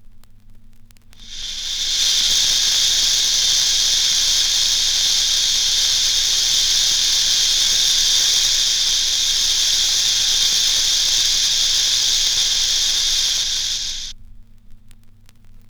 • snake hissing.wav
snake_hissing_I6i.wav